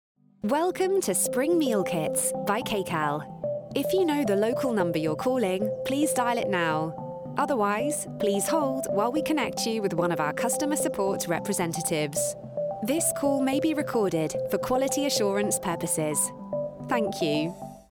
English (British)
IVR
Bright, warm, fresh, natural and professional are just some of the ways my voice has been described.